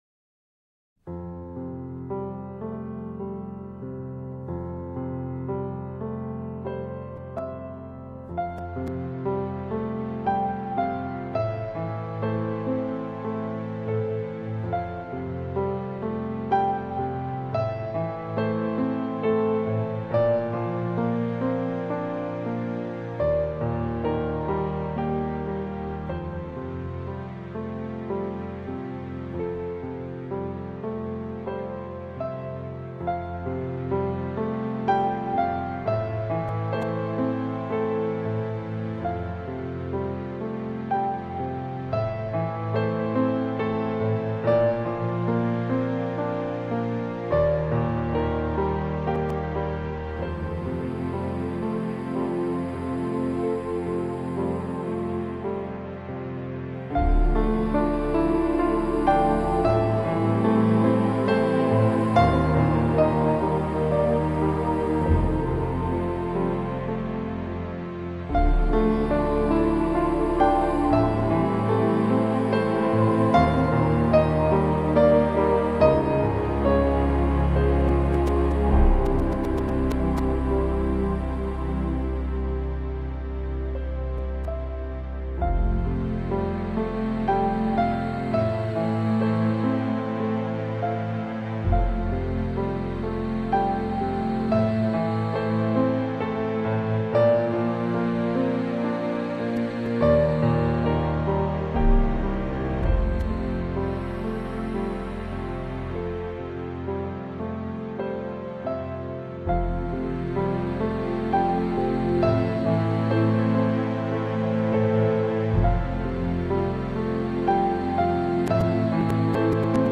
在宁静有致的钢琴声中，一波波人声袭来， 彷佛平静的水面，泛起一波波涟漪，袭袭的微风，吹拂过一望无际的水面。
演奏曲